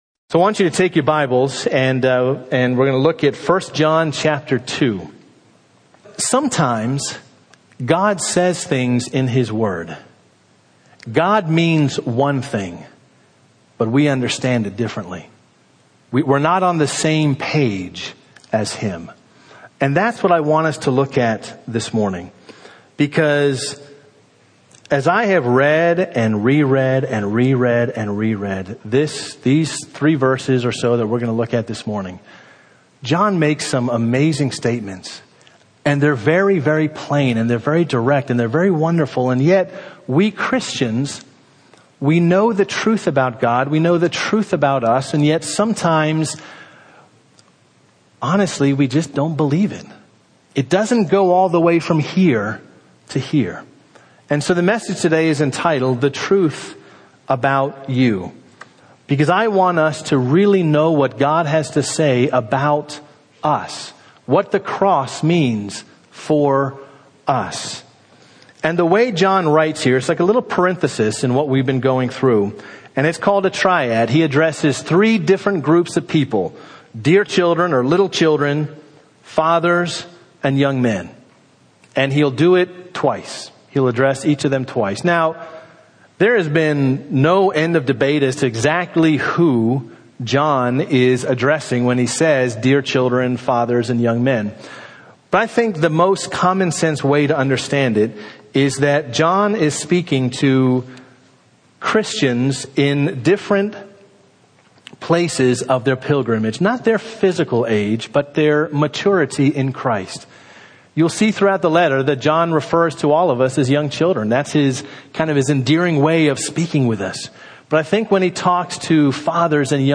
Sermons (audio) — Derwood Bible Church